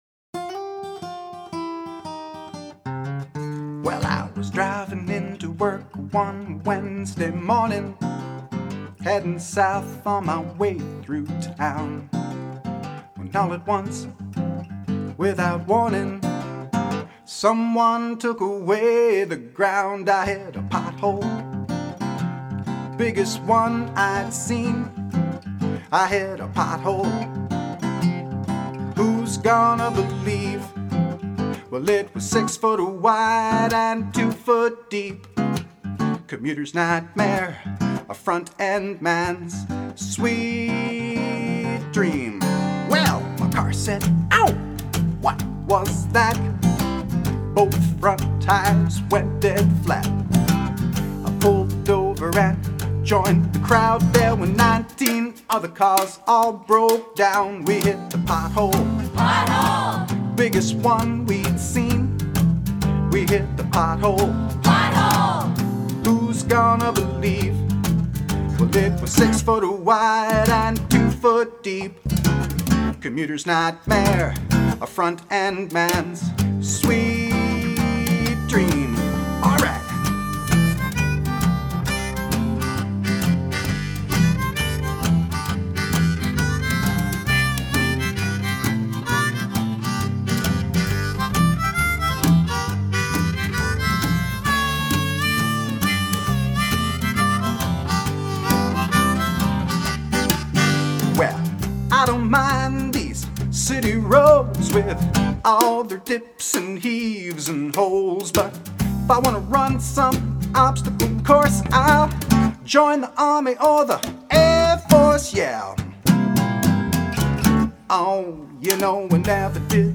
bass guitar
percussion
backing vocals
lead vocals, acoustic guitar & harmonica.
Recorded in 1995